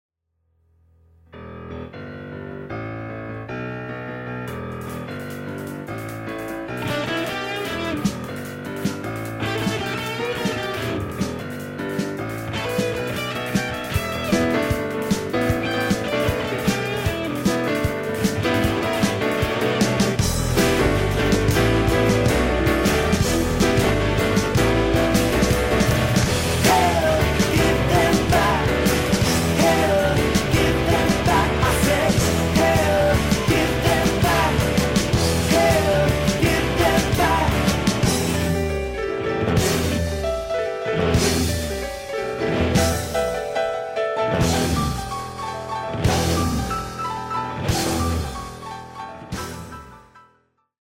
Piano/Keyboard/Hammon B3 Organ & Lead Vocals